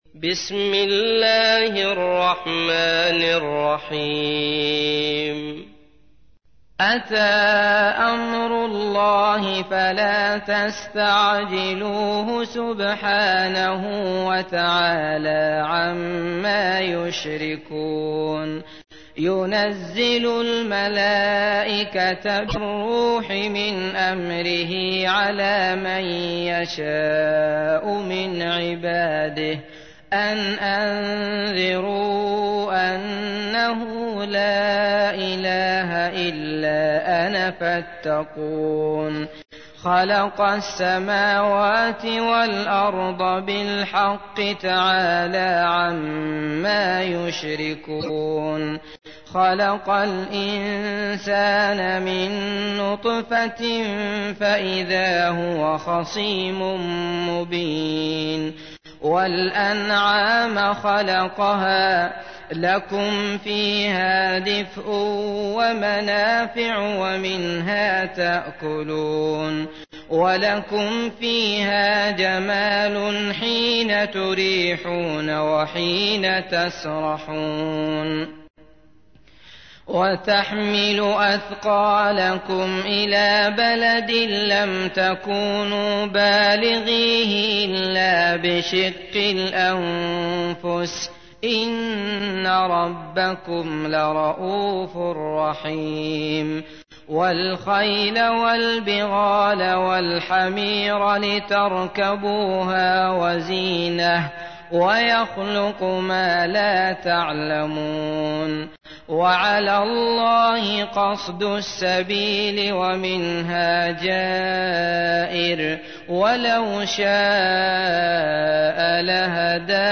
تحميل : 16. سورة النحل / القارئ عبد الله المطرود / القرآن الكريم / موقع يا حسين